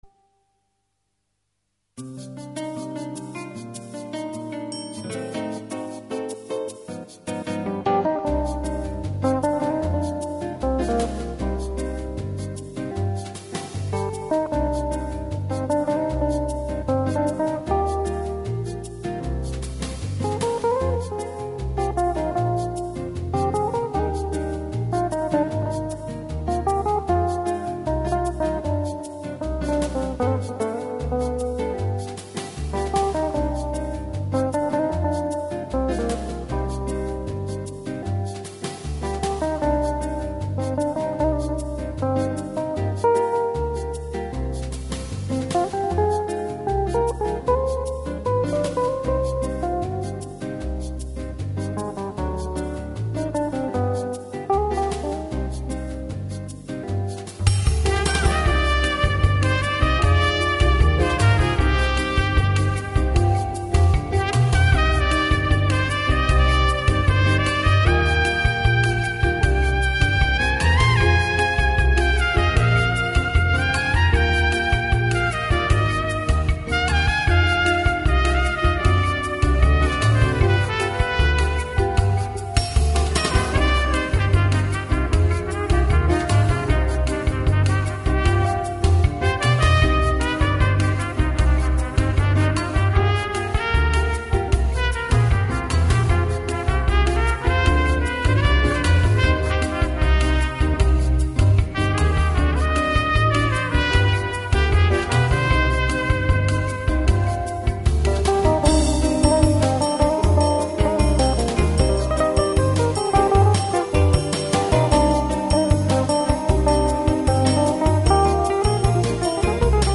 in multiplay recording system